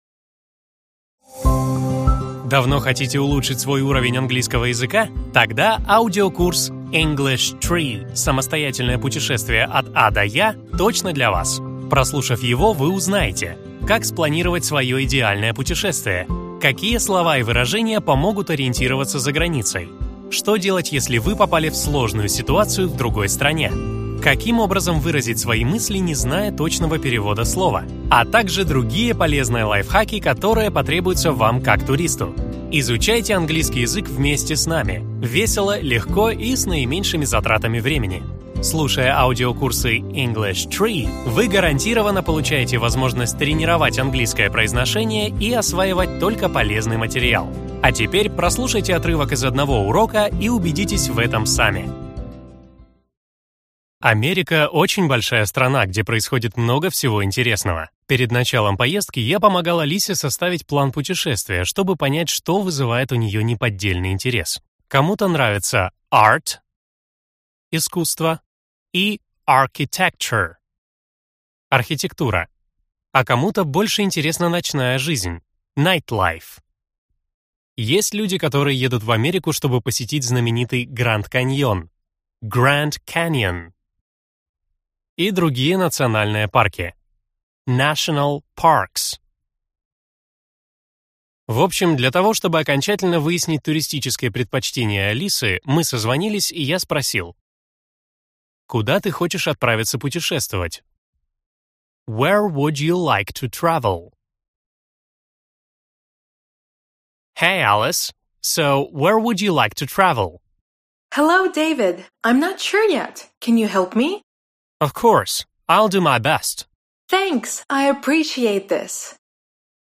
Аудиокнига Английский язык. Аудиосериал-самоучитель English Tree. Путешествие от А до Я | Библиотека аудиокниг